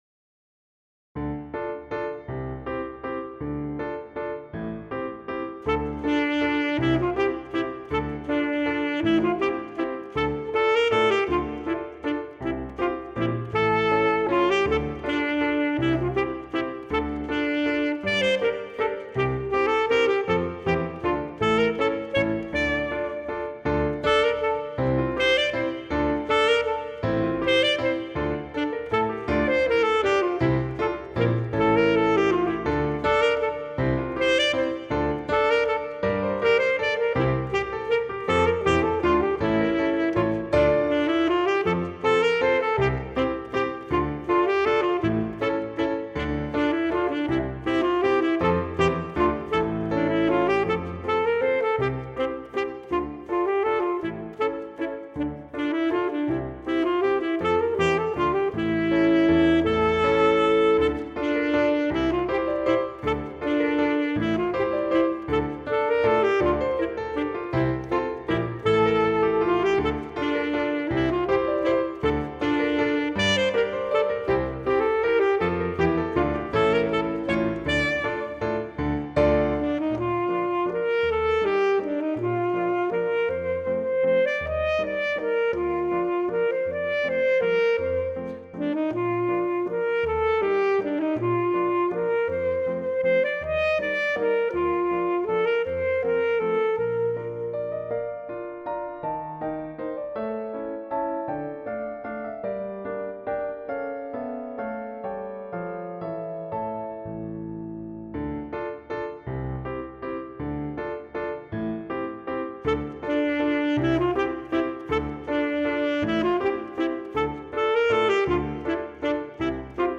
Voicing: Alto Saxophone or Bari Saxophone and Piano